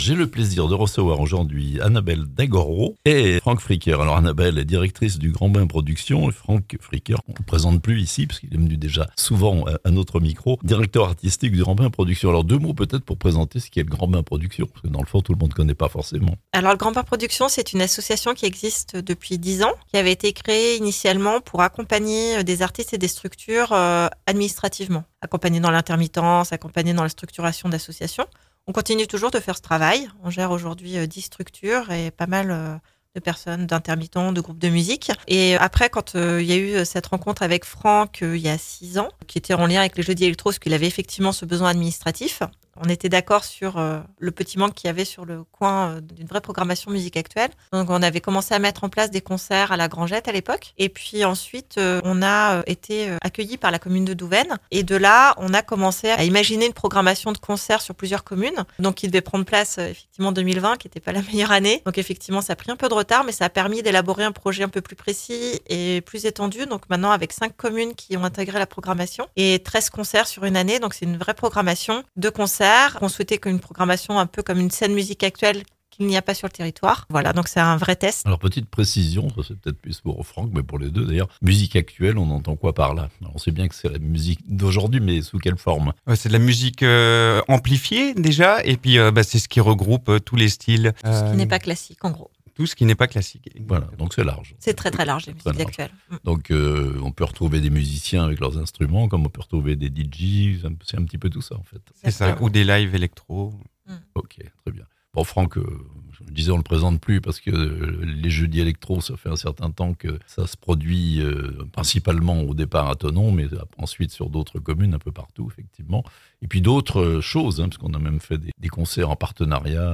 Les musiques actuelles prennent leur place en Chablais (interviews)